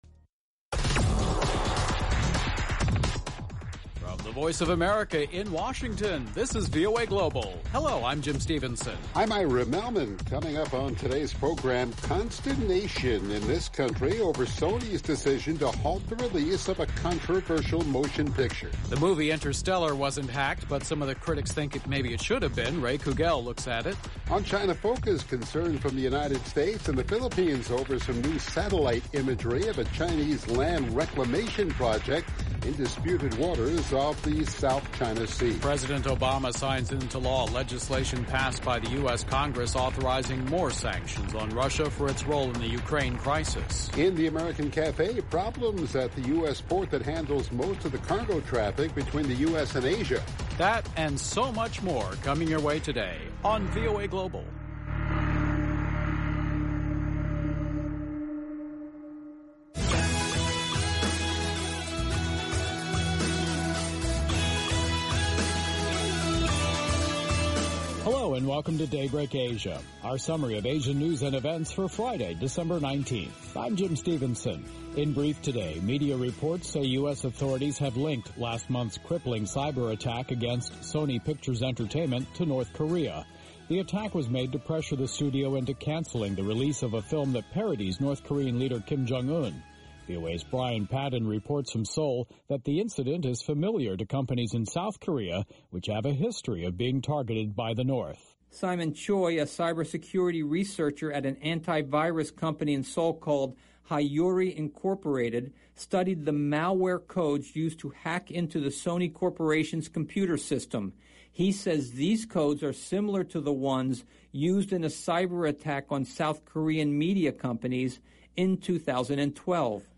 Join the VOA Global team for an informative English morning program, filled with unique stories from around the world. With the English language becoming more widely used in influential circles, VOA Global gives you a new way to learn about issues of interest in your home and around the world.